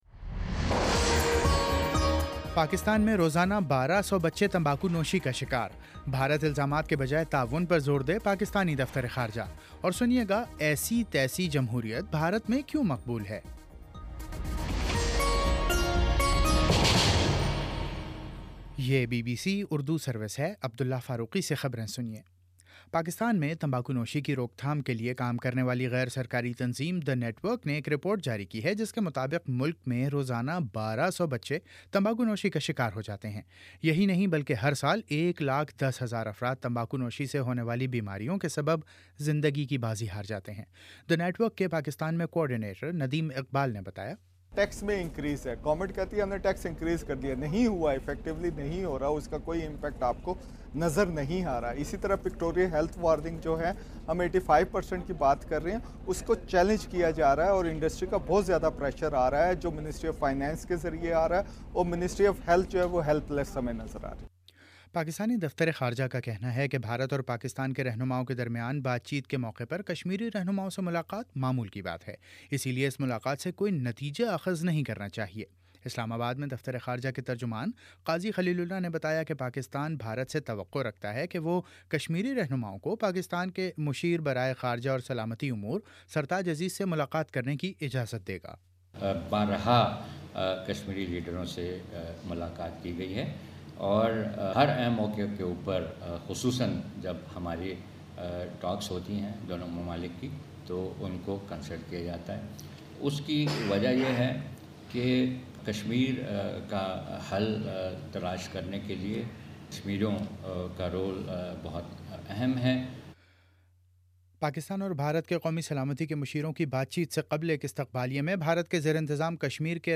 اگست 20: شام چھ بجے کا نیوز بُلیٹن